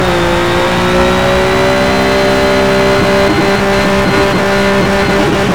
Index of /server/sound/vehicles/lwcars/porsche_911_rsr